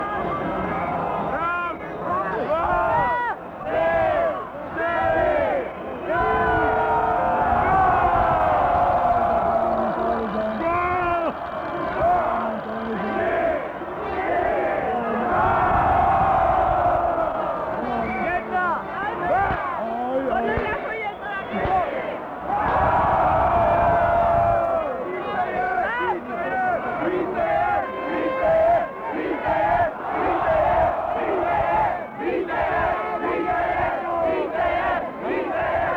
V Archivu bezpečnostních složek byla nedávno dokončena digitalizace pásků obsahujících zvukové nahrávky pořízené na Václavském náměstí (fond A 34 i.j. 2665). Vybrali jsme několik lépe srozumitelných úseků, které přibližují atmosféru tohoto večera. Terčem skandování se stal hlavně sovětský trenér Anatolij Tarasov, ale pochopitelně i Sovětský svaz samotný.